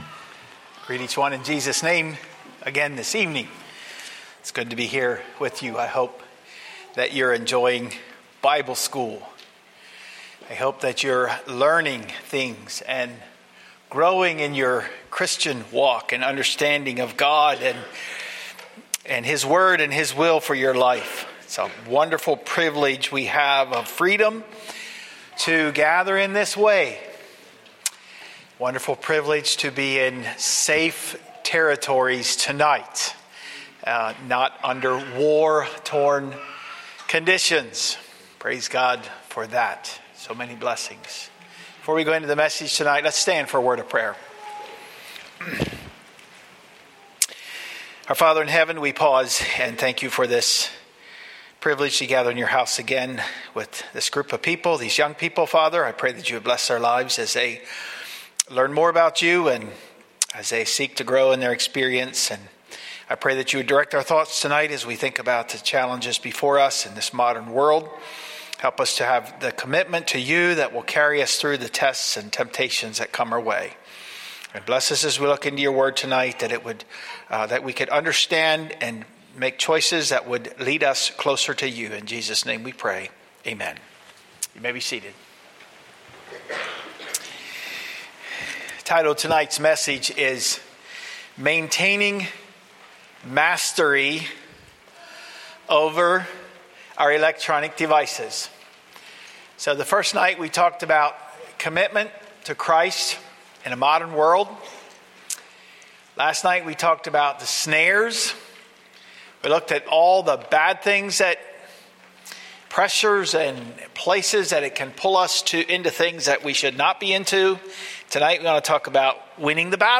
Living Hope | Sermon